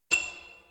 crafting.ogg